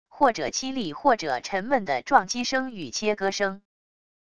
或者凄厉或者沉闷的撞击声与切割声wav音频